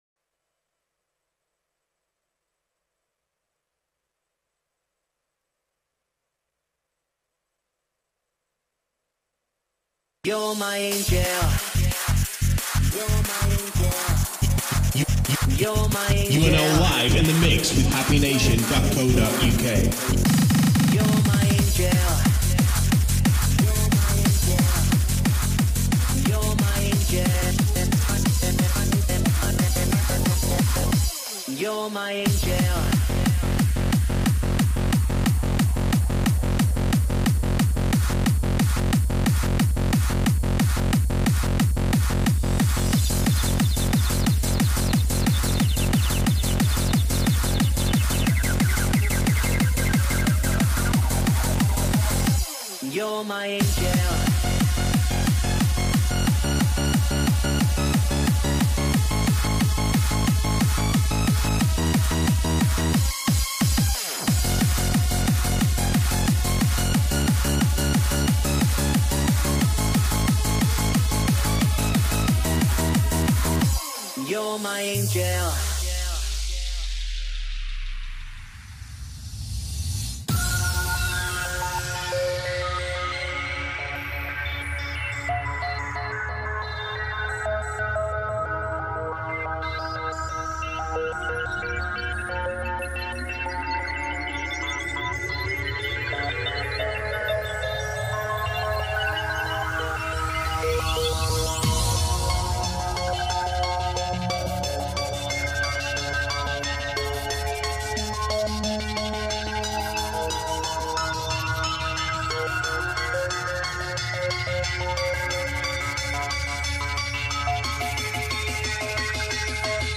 New hardcore beats!!!
Hardcore Rave Vocals